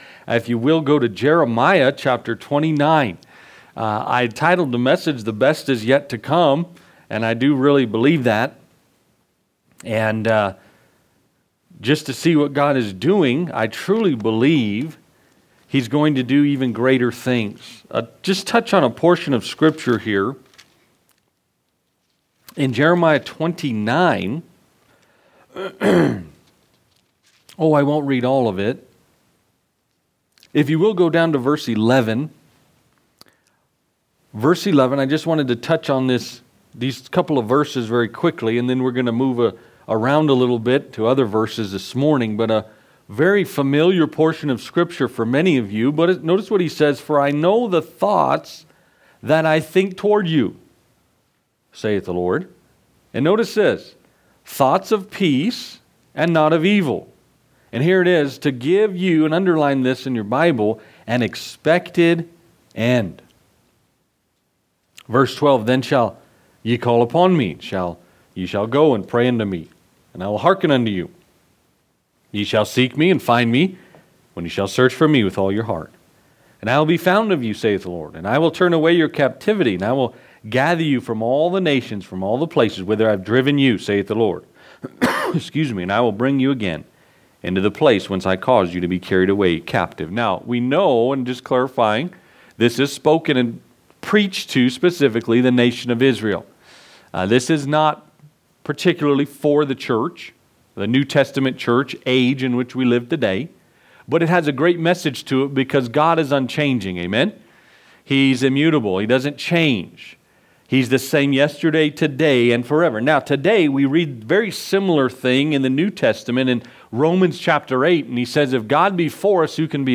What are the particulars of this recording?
Anniversary Sunday